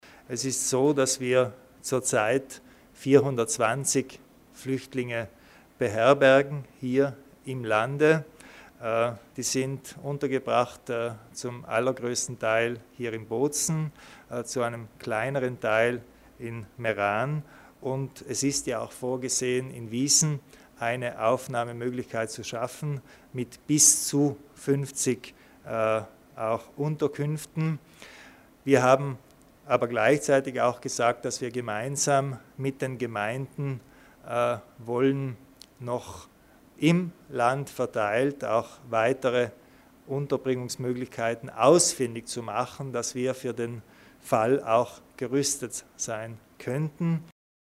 Landeshauptmann Kompatscher erläutert die Maßnahmen für die Flüchtlingshilfe